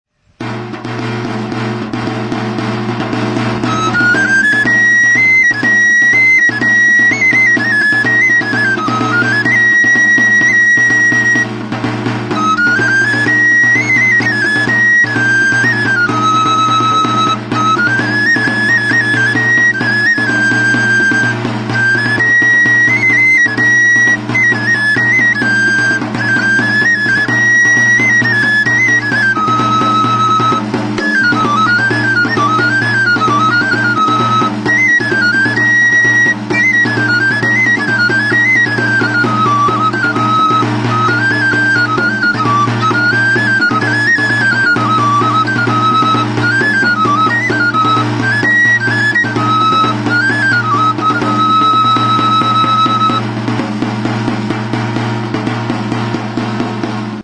EUROPE -> GAZTELA ETA LEON
FLAUTA DE TAMBORILERO
Aerophones -> Flutes -> Fipple flutes (one-handed)
Hiru zuloko flauta zuzena da.
Txistuaren digitazioan oinarriturik, Sol#'n afinaturik dago.